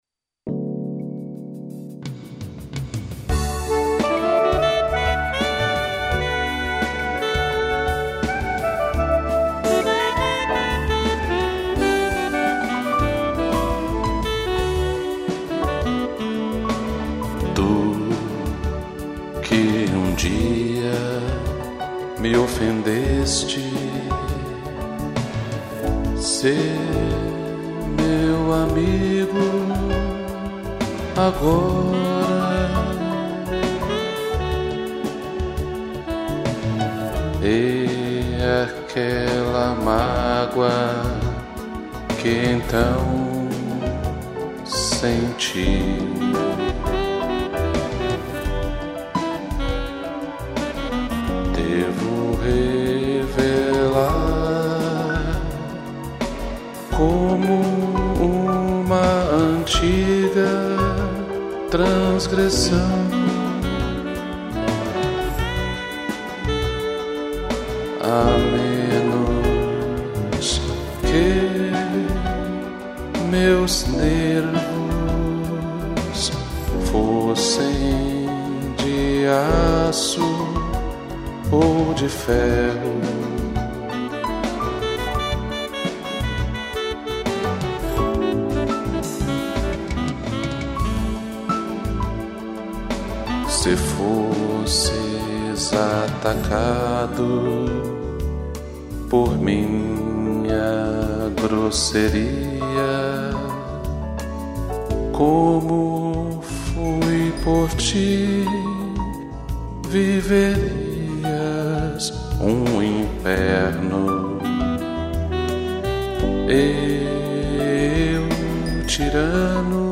Voz
piano e sax